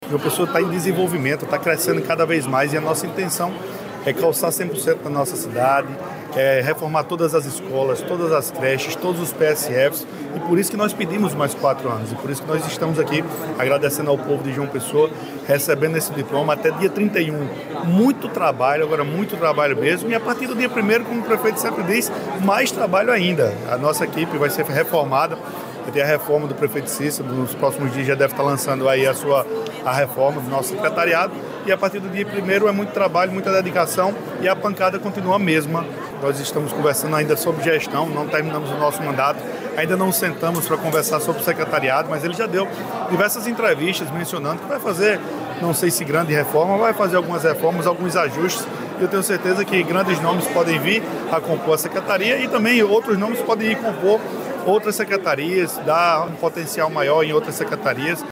O comentário do gestor foi registrado pelo programa Correio Debate, da 98 FM, de João Pessoa, nesta terça-feira (17/12).